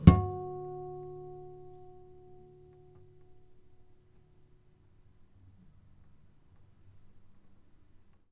harmonic-08.wav